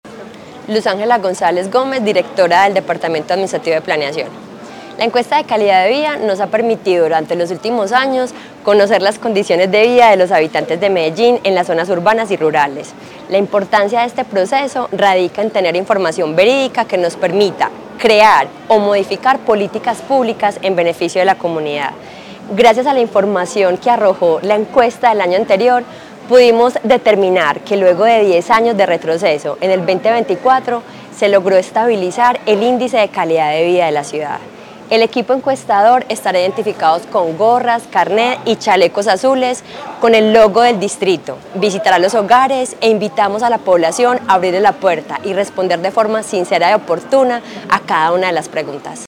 Declaraciones directora del Departamento Administrativo de Planeación, Luz Ángela González Gómez
Declaraciones-directora-del-Departamento-Administrativo-de-Planeacion-Luz-Angela-Gonzalez-Gomez.mp3